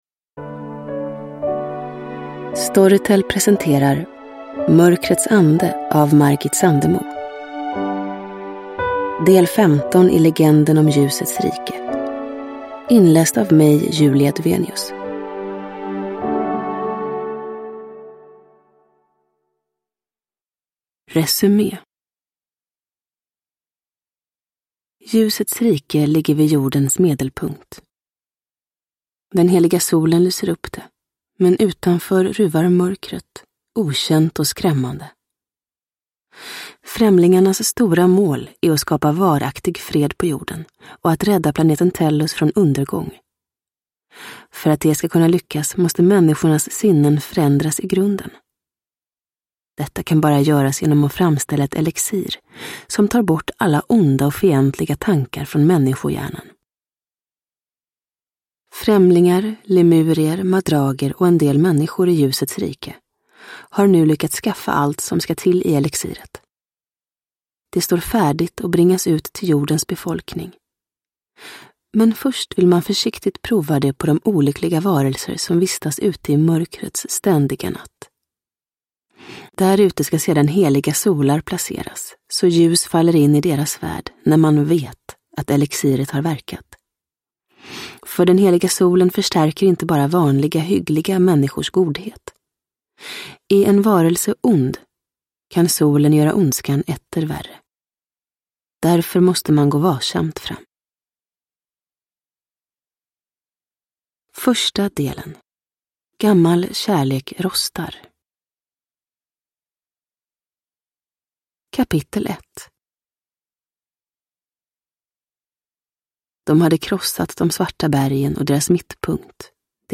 Mörkrets ande – Ljudbok – Laddas ner